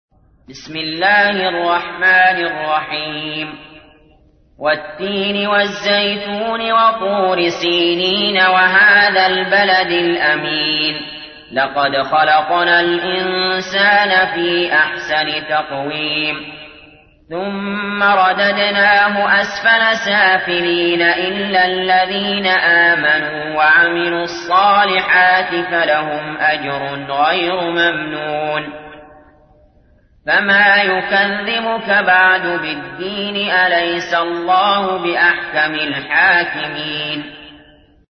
تحميل : 95. سورة التين / القارئ علي جابر / القرآن الكريم / موقع يا حسين